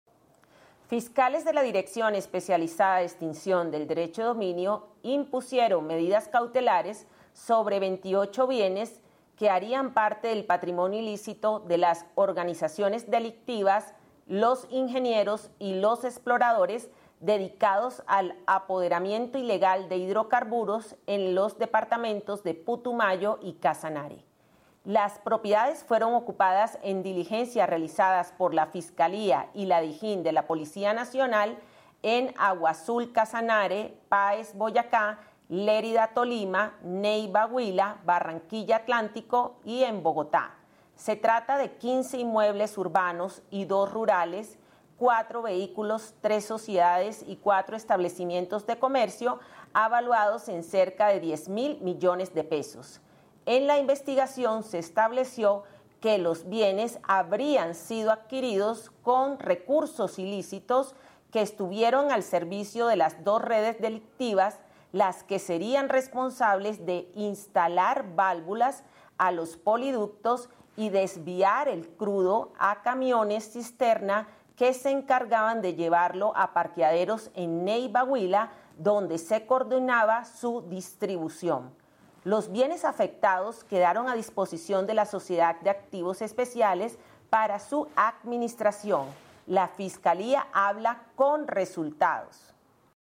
Directora Especializada de Extinción del Derecho de Dominio, Liliana Patricia Donado Sierra